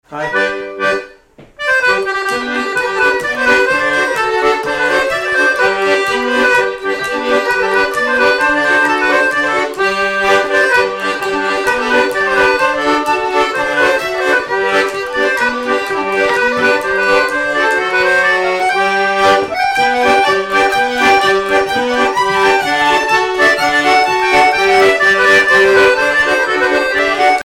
Miquelon-Langlade
danse : jig
violon
Pièce musicale inédite